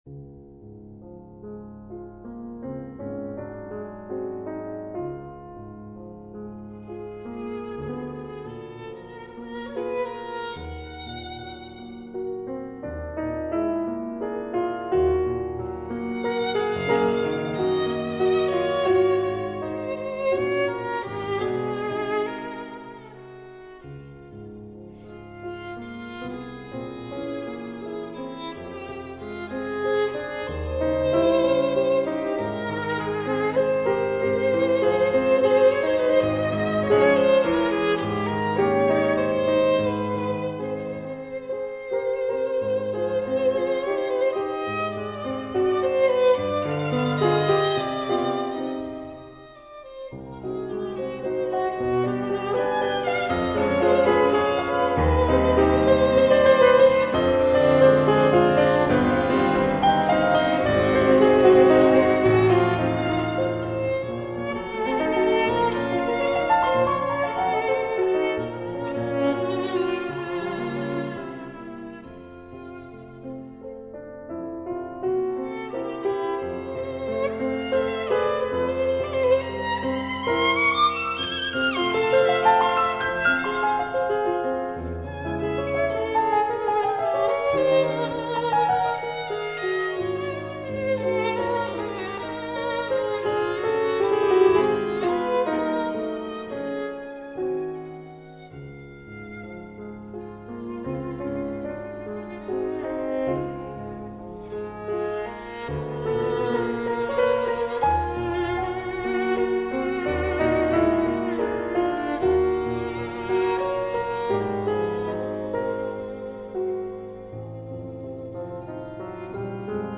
Incontro con Quirino Principe
questa prima parte dell’incontro inizia con la Prima Romanza per violino e pianoforte op. 22 interpretata da Lisa Batiashvili e Alice Sara Ott.